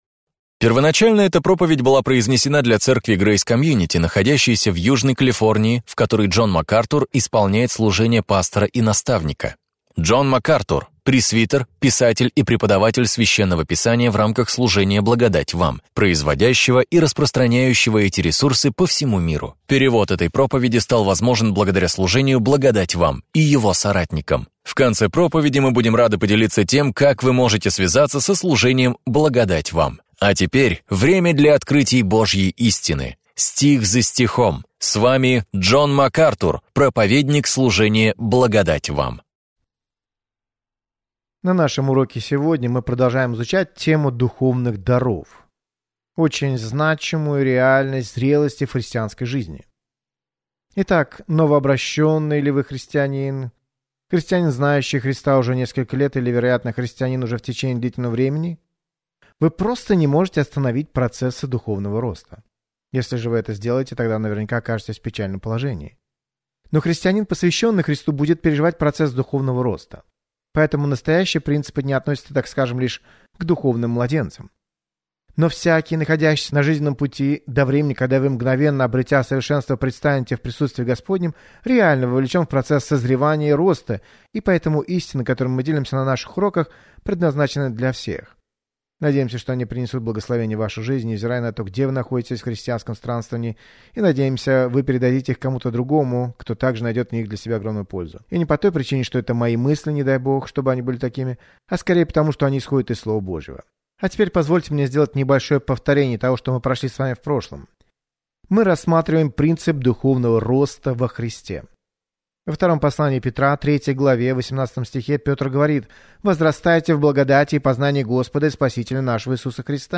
В своей проповеди «Возвращение к основам», актуальной для всякого времени, Джон Макартур делает обзор базовых принципов христианства и помогает вам сделать их основанием для своей жизни.